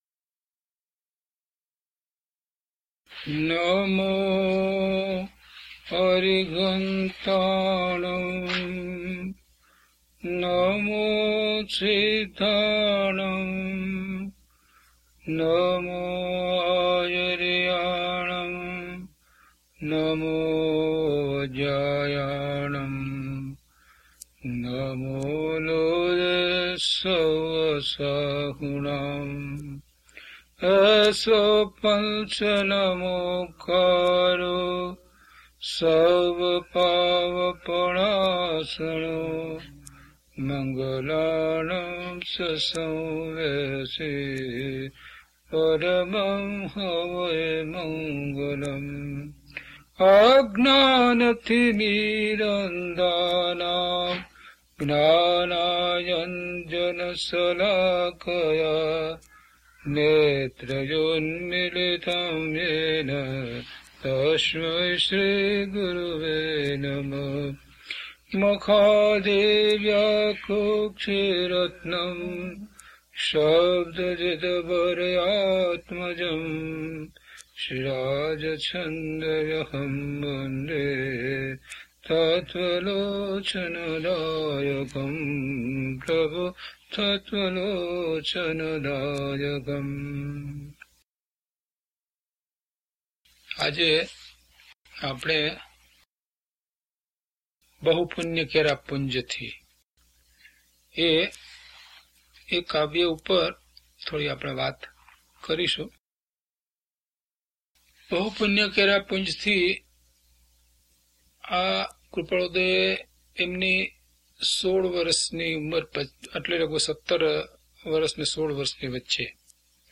DHP036 Bahu Punya Kera Punyathi - Pravachan.mp3